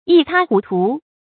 注音：ㄧ ㄊㄚ ㄏㄨˊ ㄊㄨˊ
一塌糊涂的讀法